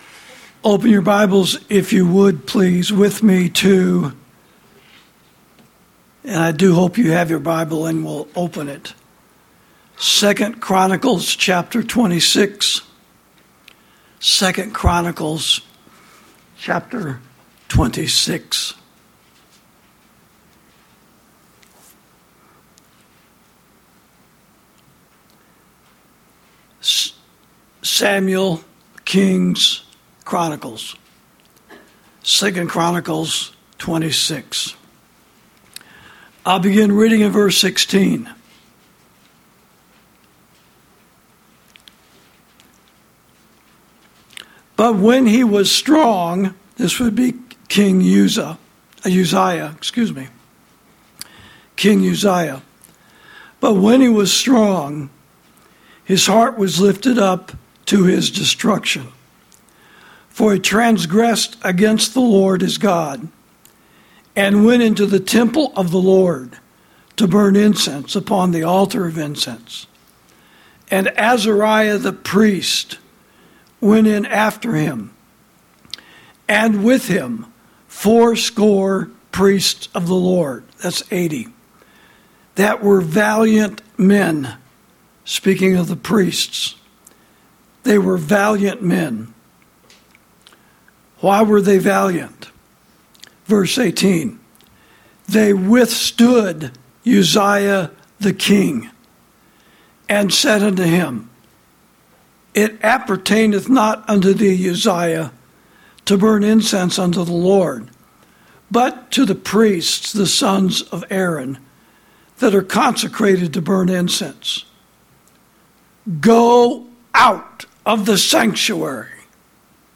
Sermons > God's Men Must Confront Government Leaders When They Usurp The Spiritual Jurisdiction Of The Sanctuary, As Exemplified By Priest Azariah And King Uzziah